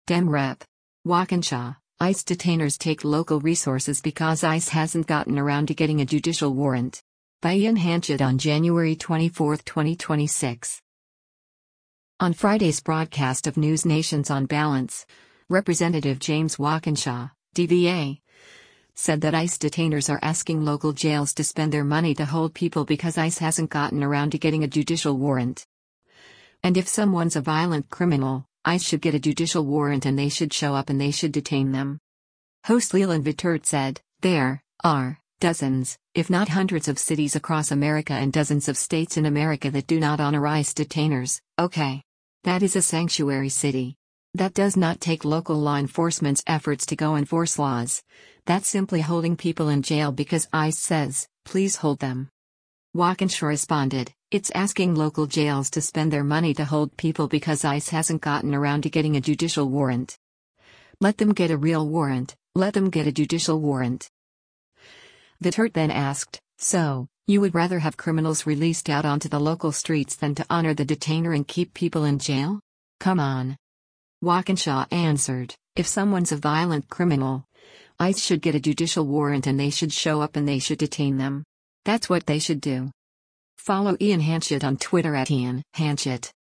On Friday’s broadcast of NewsNation’s “On Balance,” Rep. James Walkinshaw (D-VA) said that ICE detainers are “asking local jails to spend their money to hold people because ICE hasn’t gotten around to getting a judicial warrant.”